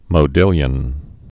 (mō-dĭlyən)